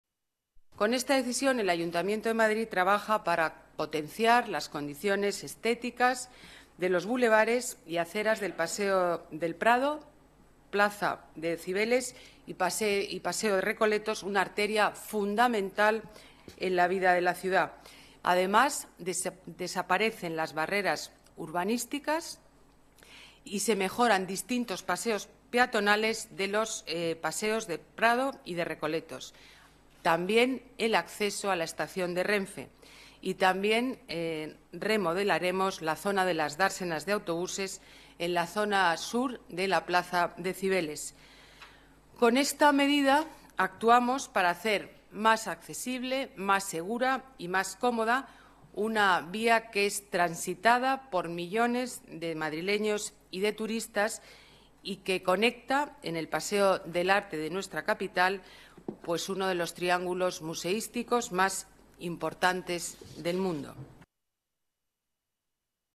Nueva ventana:Declaraciones de la alcaldesa